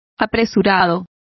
Complete with pronunciation of the translation of hastiest.